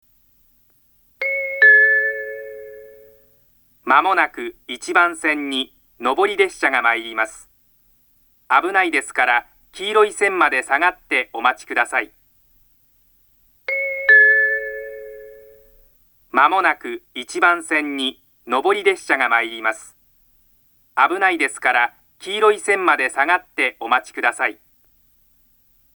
ホーム上の大きなTOA製およびPanasonic製ラッパから流れます。
接近放送
男性による接近放送です。